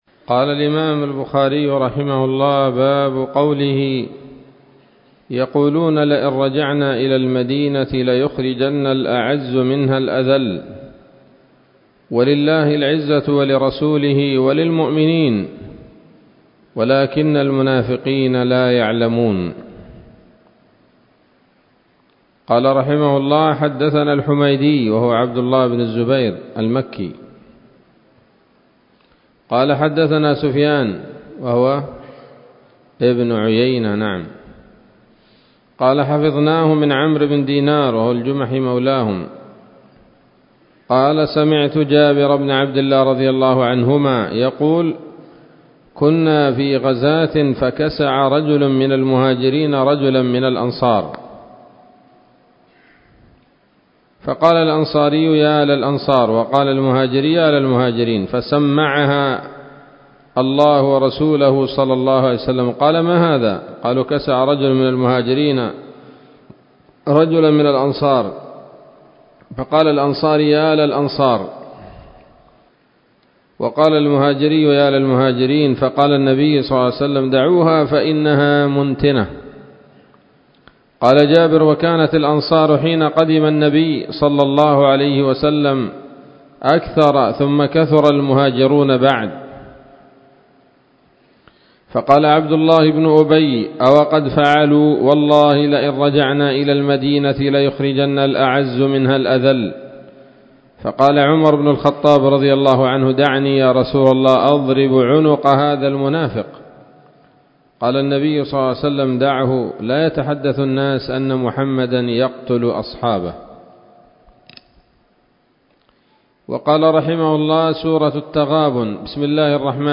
الدرس الخامس والستون بعد المائتين من كتاب التفسير من صحيح الإمام البخاري